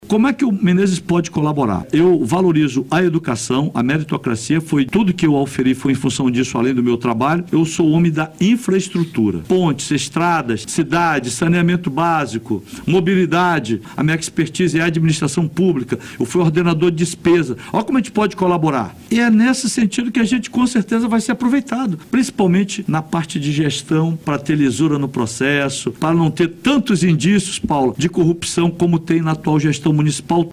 Em entrevista no BandNews Amazônia 1ª Edição nesta terça-feira, 06, Menezes negou que tenha provocado o ex-presidente.